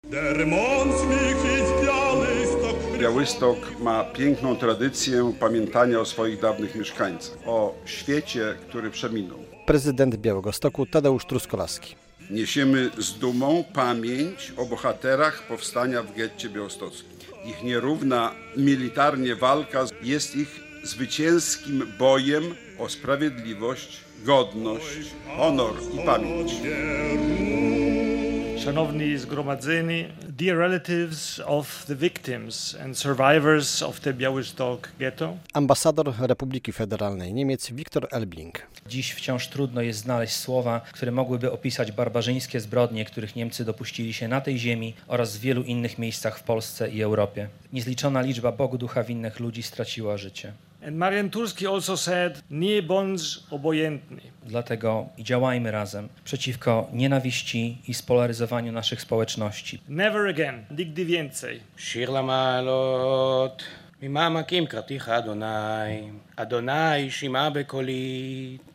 81. rocznica powstania w getcie - relacja